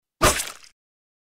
Звуки удара ножом
Взмах лезвием и проникновение в тело